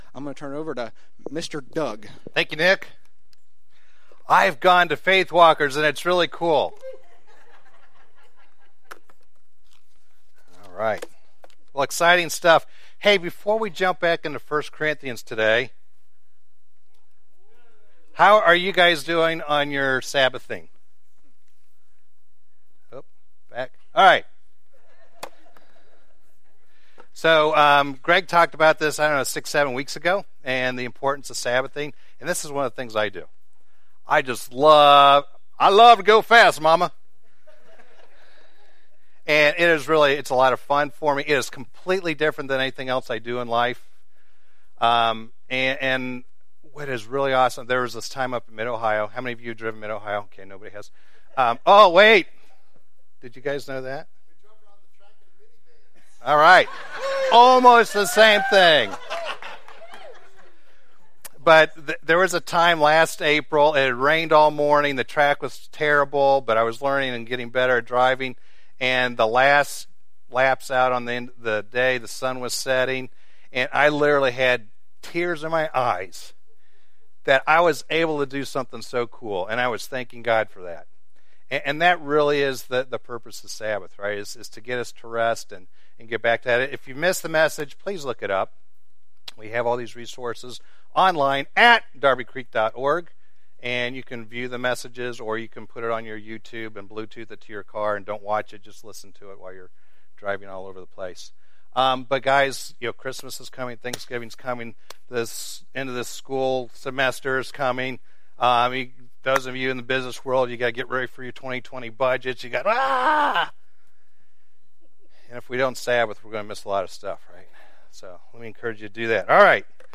A message from the series "Church Matters."